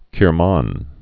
(kĭr-män, kər-)